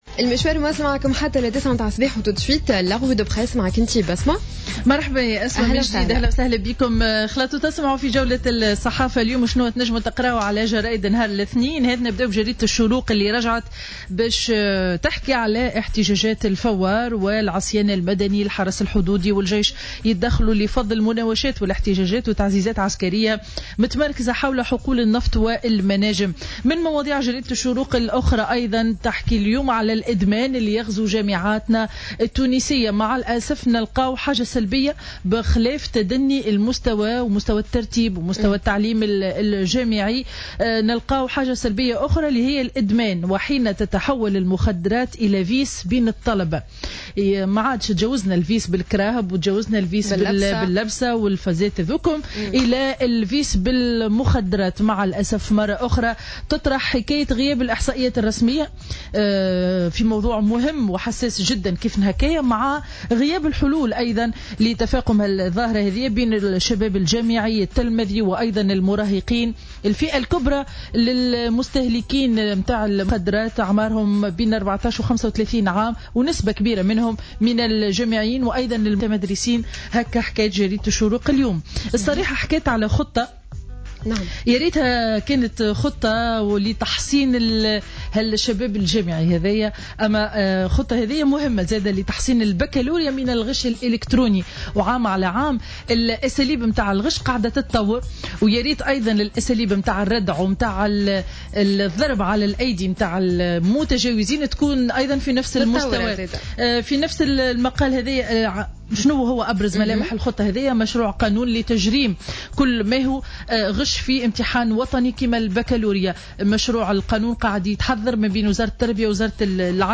معرض الصحافة ليوم الاثنين 11 ماي 2015